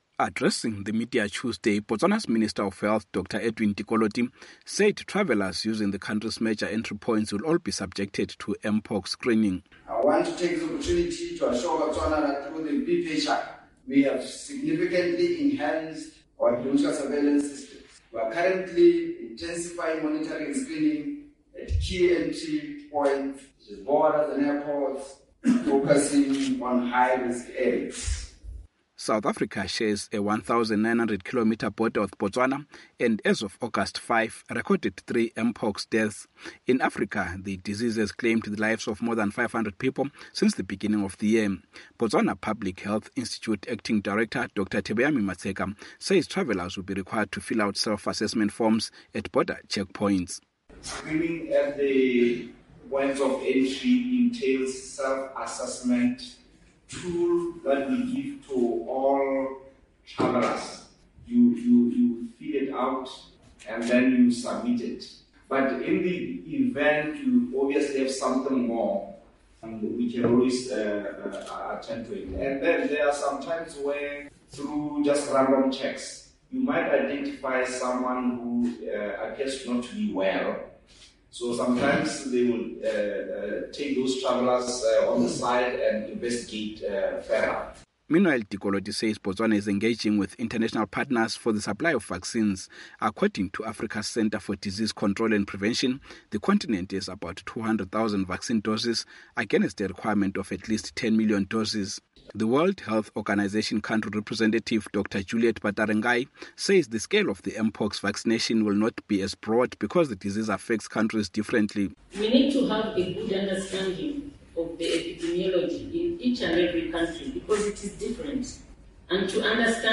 Botswana has no reported cases of mpox, but health officials have started screening for the deadly disease at its entry points. Neighboring South Africa has recorded three deaths as a result of the dangerous type of mpox and Botswana is anxious to keep out the rapidly spreading disease. From Gaborone